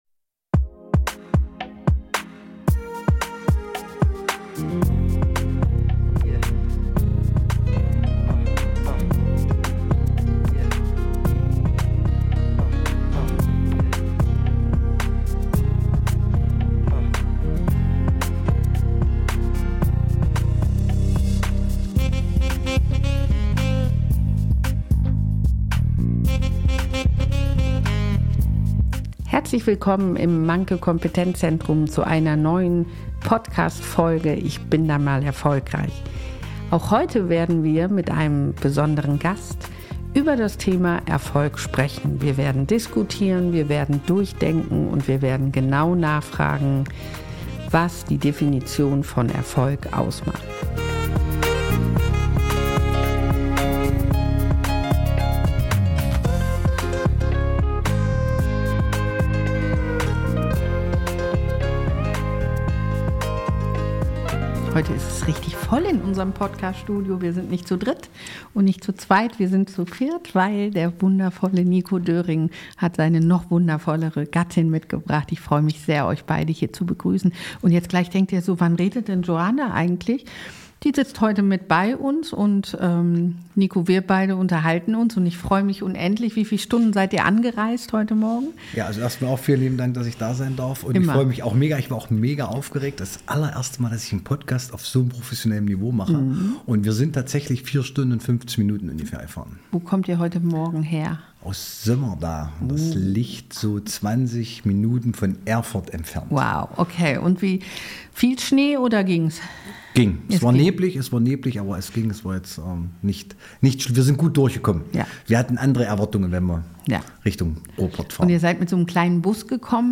Ein besonders persönliches Gespräch über die Herausforderungen und Glücksmomente im Leben, die Bedeutung von Familie, die Kraft des Respekts und den Mut, die eigenen Träume zu verfolgen.